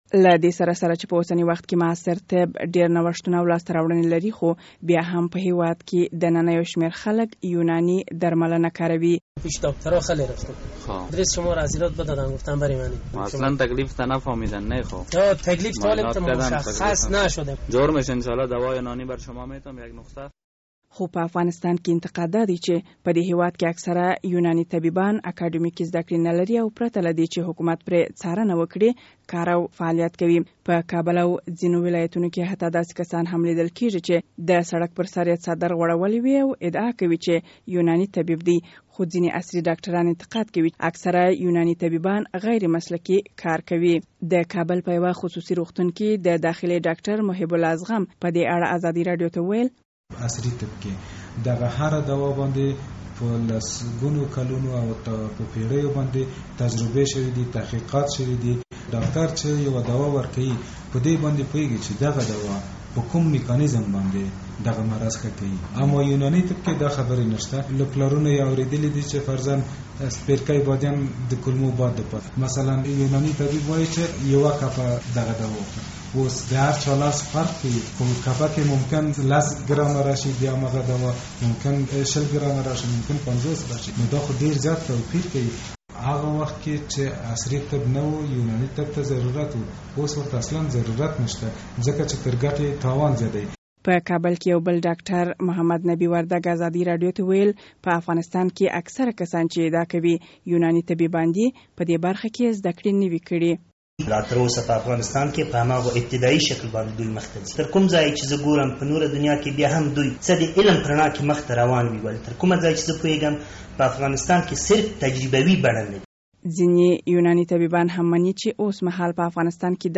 یو مفصل راپور